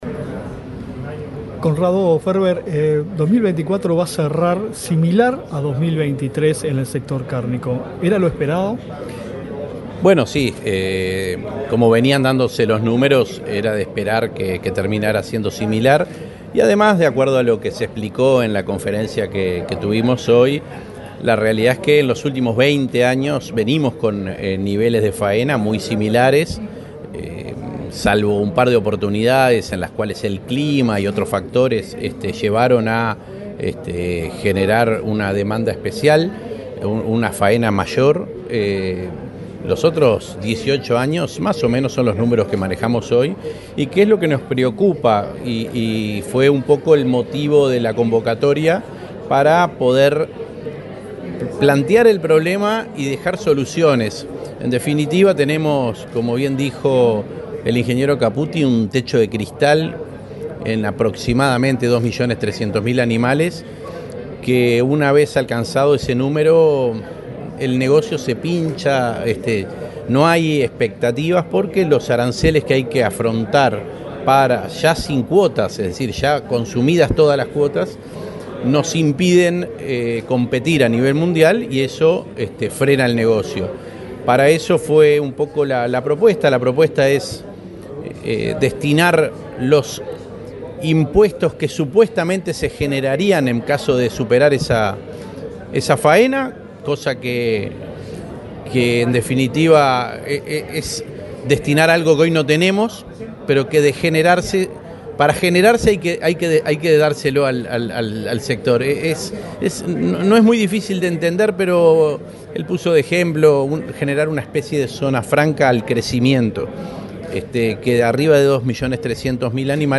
Entrevista al presidente de INAC, Conrado Ferber
El presidente del Instituto Nacional de Carnes (INAC), Conrado Ferber, dialogó con Comunicación Presidencial, acerca del cierre del año estadístico de